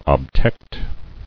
[ob·tect]